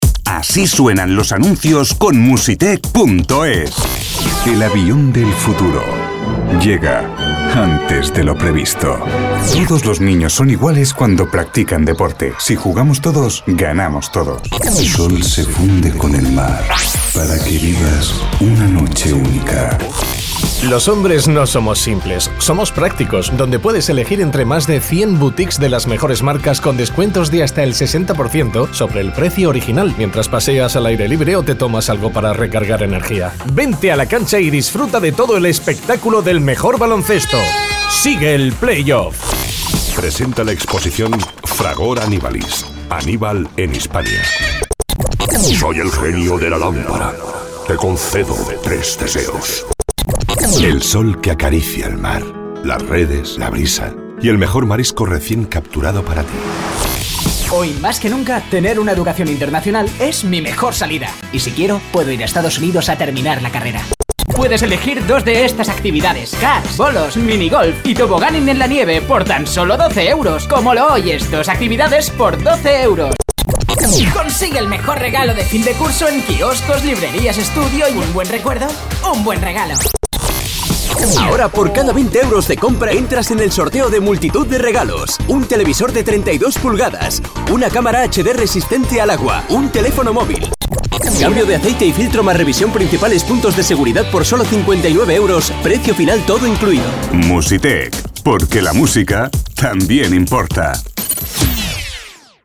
Realizamos las cuñas que gustan a tus clientes.